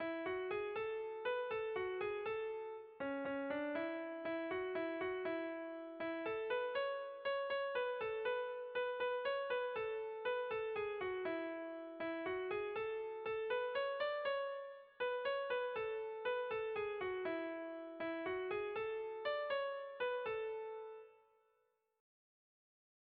Irrizkoa
Kopla handia
A-B-C-D